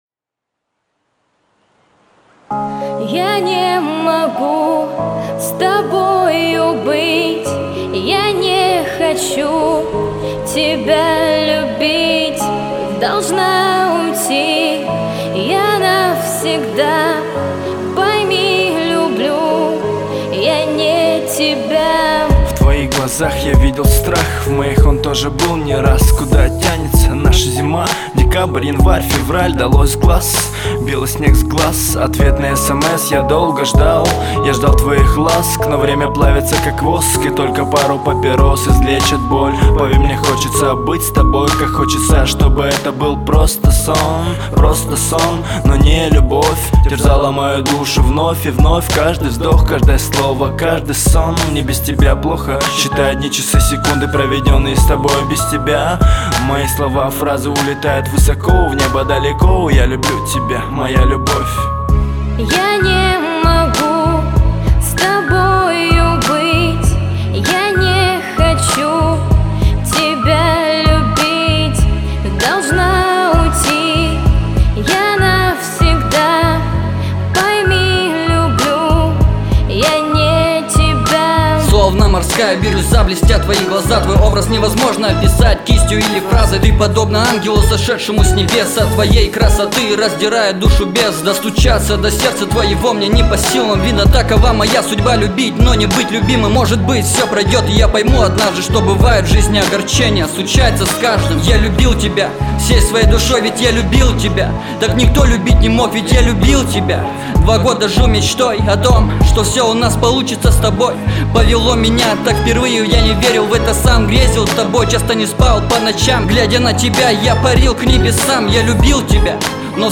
красиво_поет_девушка_-_я_не_могу_с_тобою_бытья_не_х
krasivo_poet_devushka___ya_ne_mogu_s_tobojy_bytjqya_ne_h.mp3